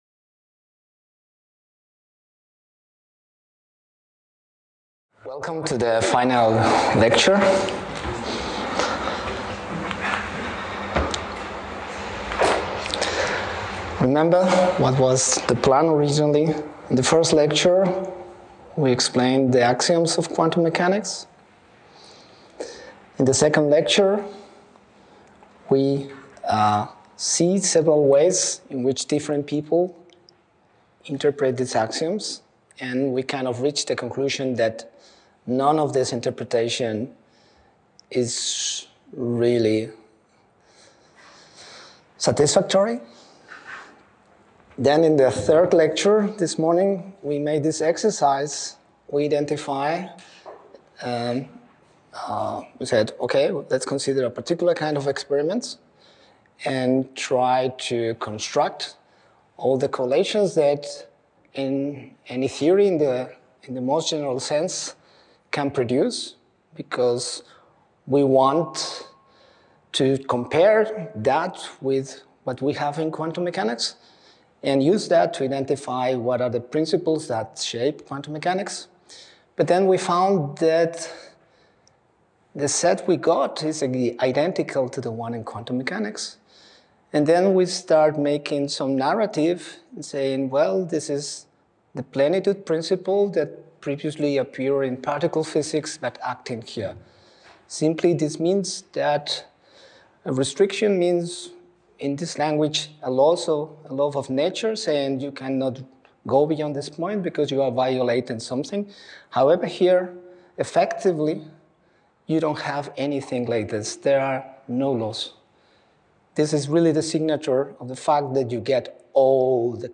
Aprofitant que el 2025 és l'Any Internacional de la Ciència i les Tecnologies Quàntiques aquestes conferències ens proposen acostar la teoria quàntica a qualsevol persona interessada en la física i el coneixement en general.